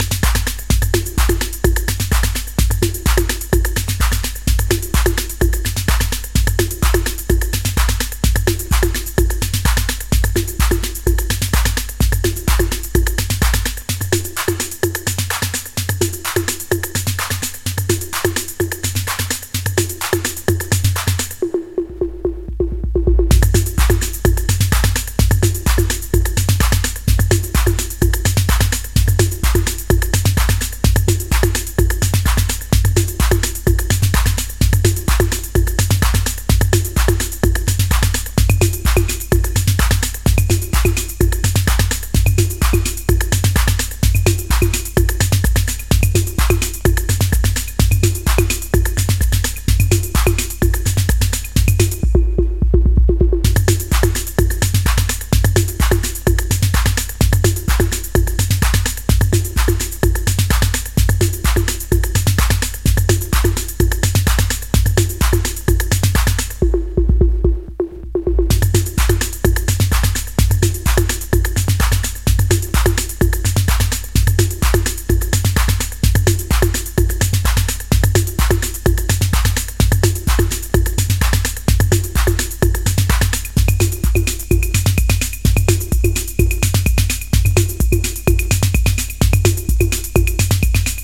4 raw stripped down Chicago acid cuts